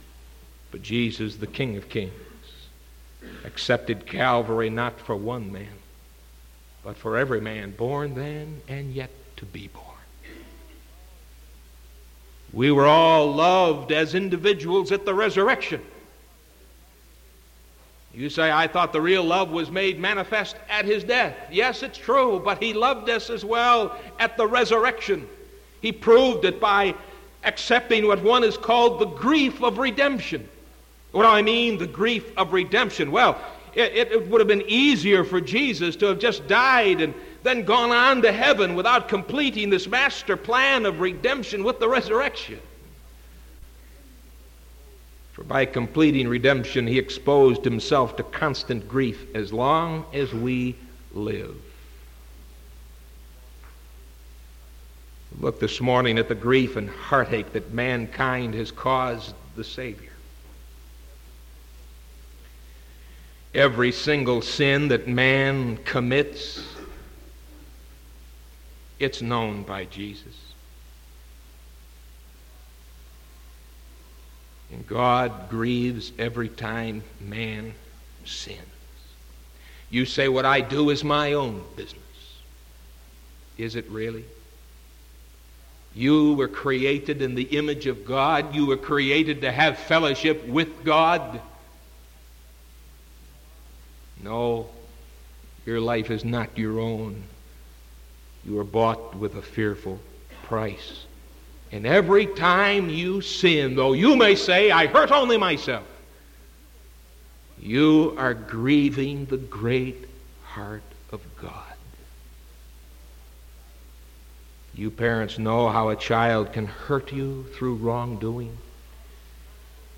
Sermon January 12th 1975 AM